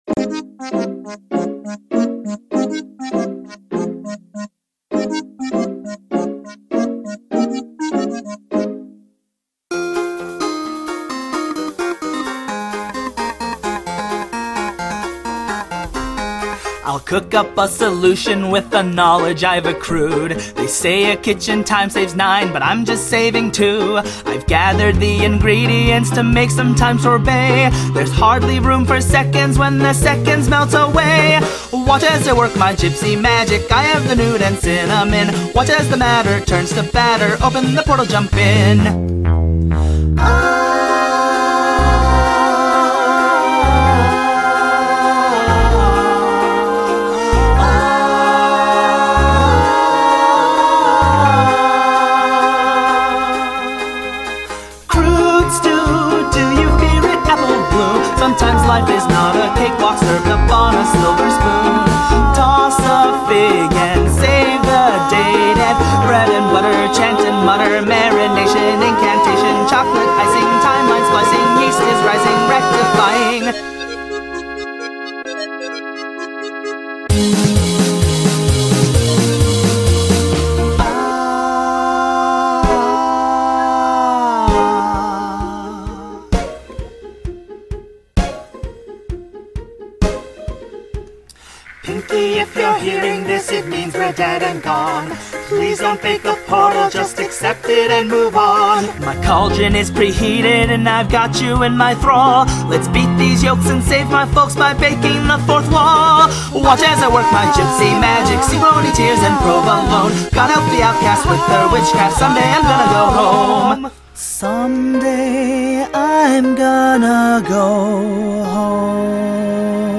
wonderfully happy sad song